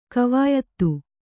♪ kavāyattu